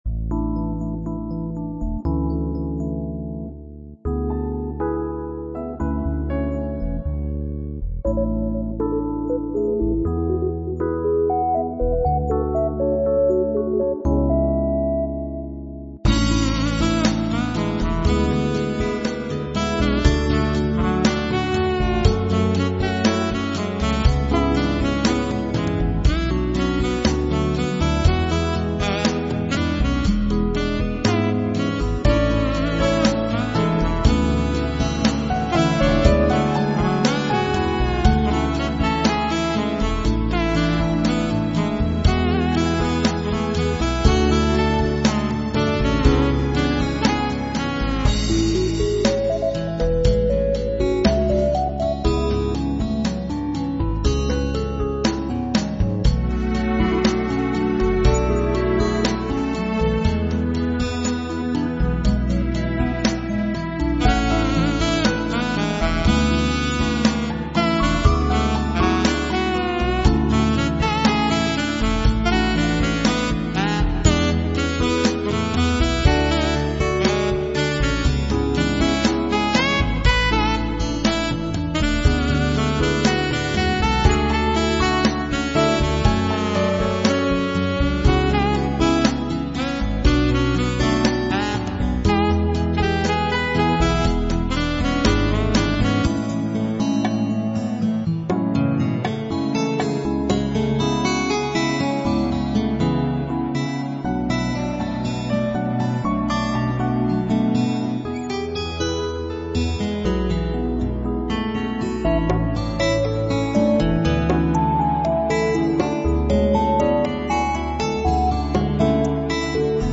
Relaxed Soundtrack music in style of soft Pop Ballad